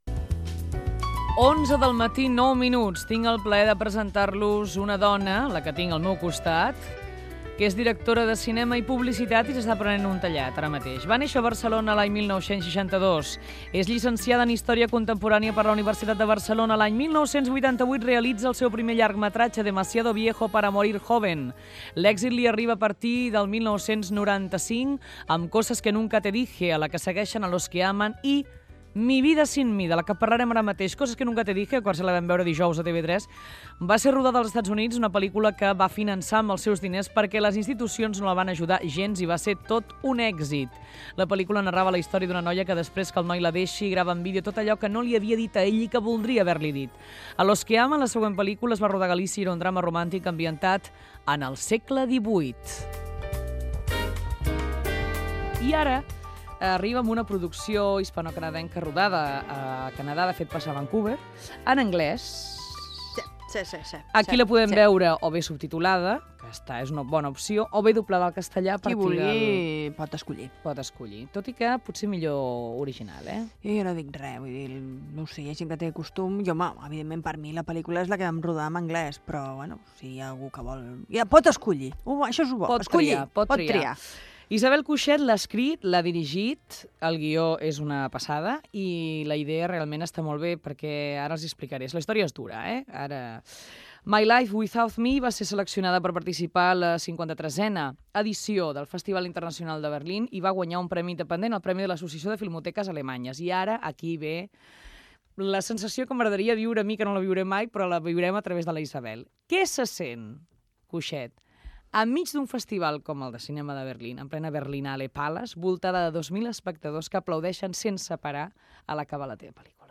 Fragment d'una entrevista a la directora de cinema Isabel Coixet per la seva pel·lícula "Mi vida sin mí"